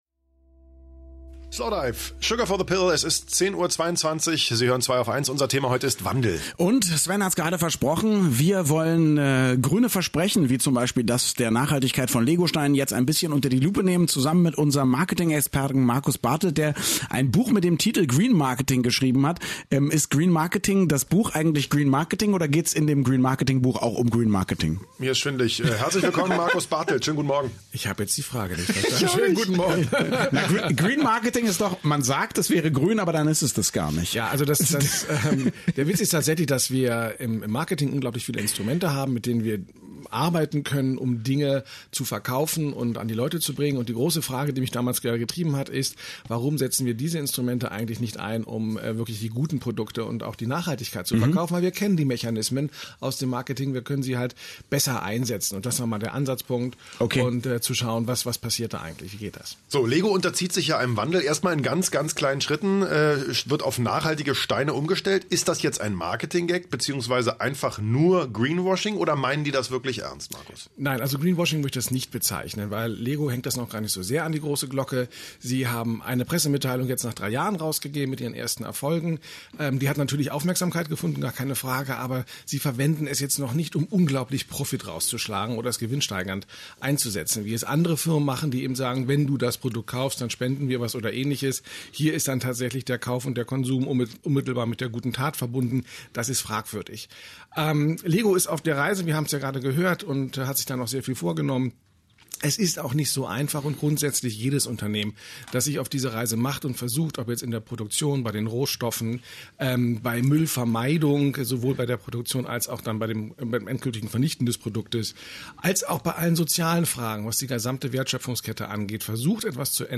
„Wandel“ lautet das heutige Thema der „Zweiaufeins„-Sendung und ich habe mich in Richtung Babelsberg zum radioeins-Studio aufgemacht, um über den Wandel in Sachen Nachhaltigkeit zu sprechen: